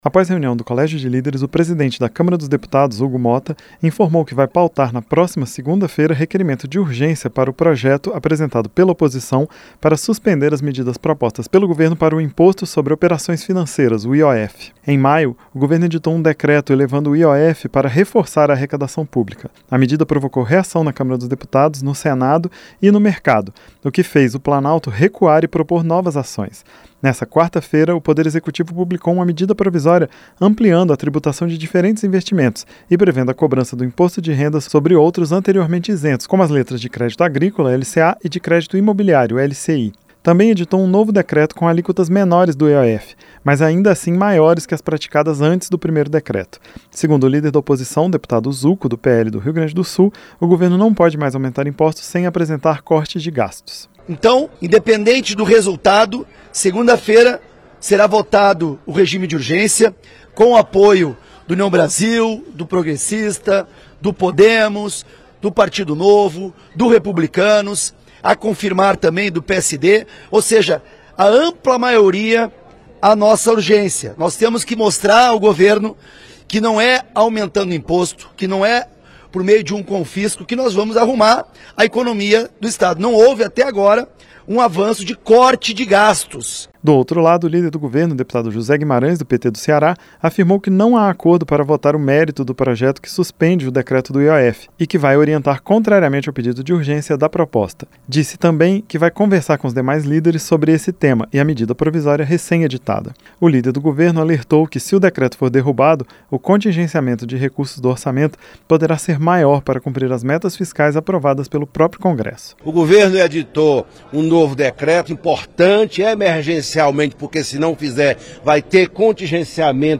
A OPOSIÇÃO NA CÂMARA QUER DERRUBAR O NOVO DECRETO SOBRE O IOF. JÁ O GOVERNO BUSCA ENTENDIMENTO. AS INFORMAÇÕES COM O REPÓRTER